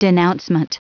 Prononciation du mot denouncement en anglais (fichier audio)
Prononciation du mot : denouncement